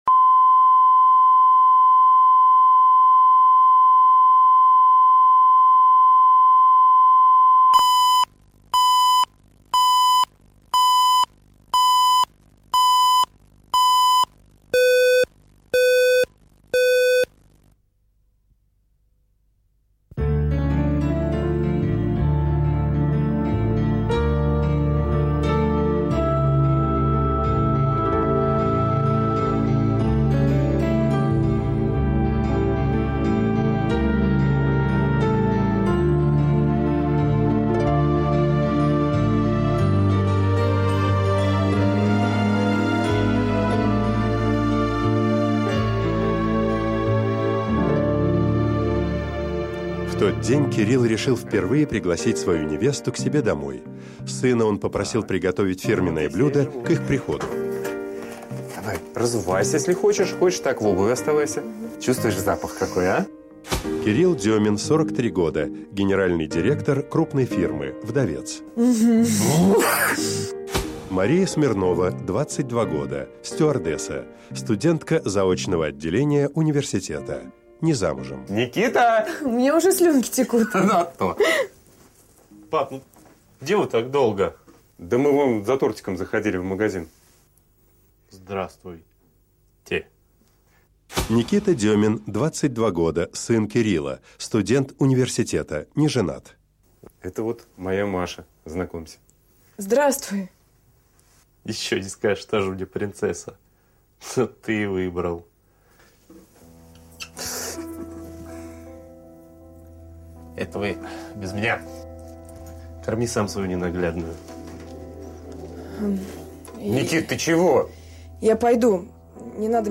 Аудиокнига Полчаса до счастья | Библиотека аудиокниг
Прослушать и бесплатно скачать фрагмент аудиокниги